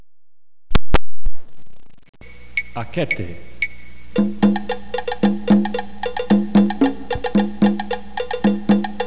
The time signature for this rhythm is 4/4 which means each measure gets four beats or counts, each quarter note gets one beat and each eighth note gets one half of a beat or count. The rests in the Akattá rhythm are quarter rests. They are silent beats that last the same time as a quarter note would.